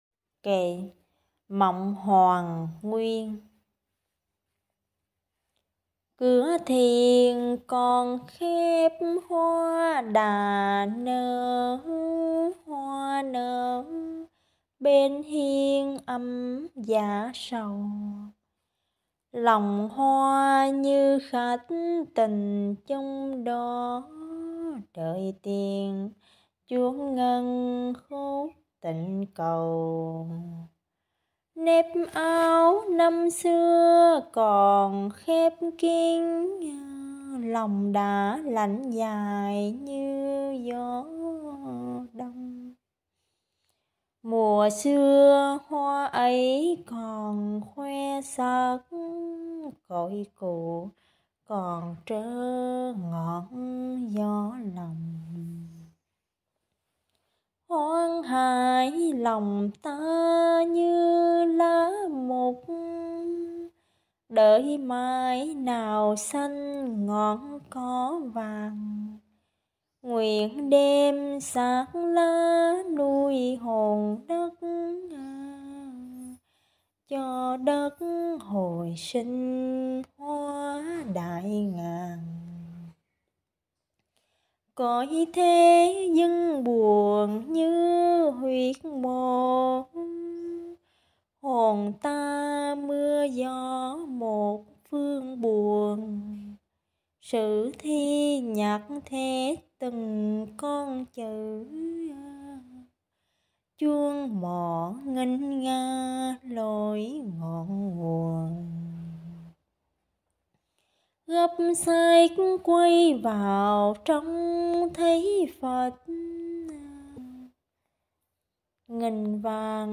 Mộng hoàn nguyên - tu là mượn đạo xây đời - đạo ca - Vị Lai Pháp